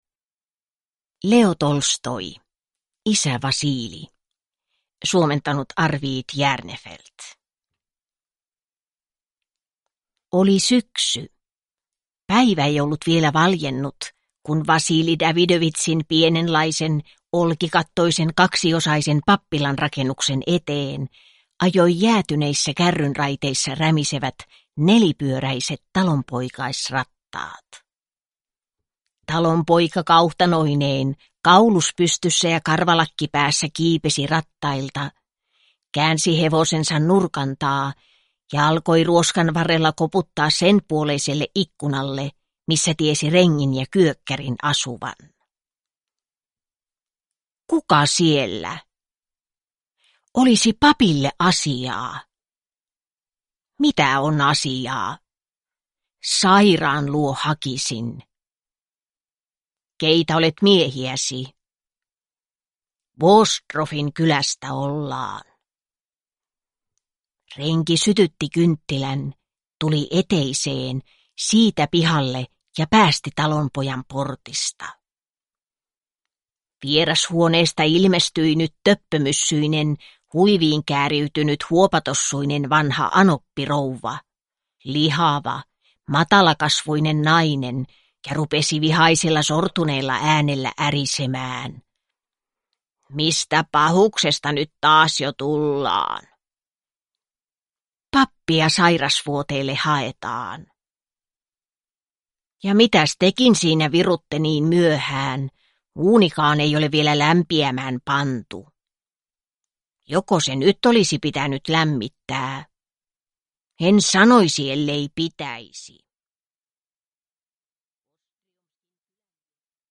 Isä Vasilij (ljudbok) av Leo Tolstoi